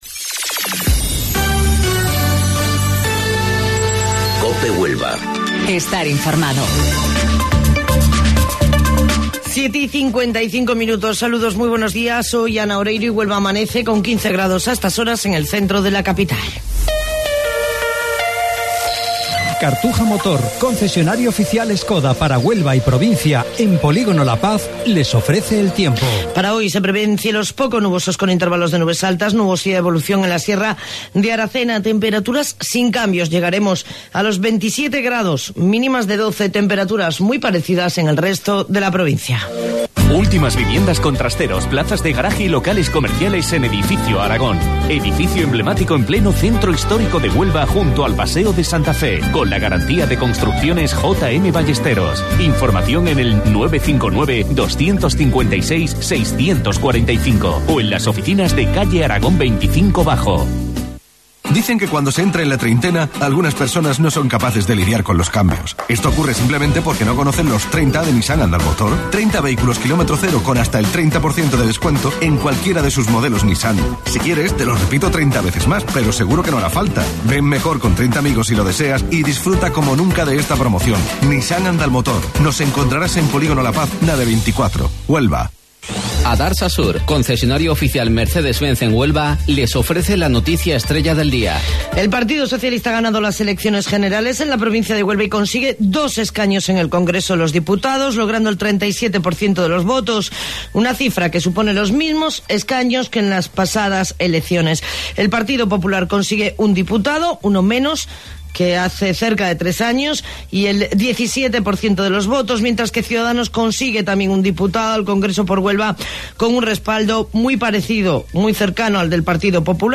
AUDIO: Informativo Local 07:55 del 29 de Abril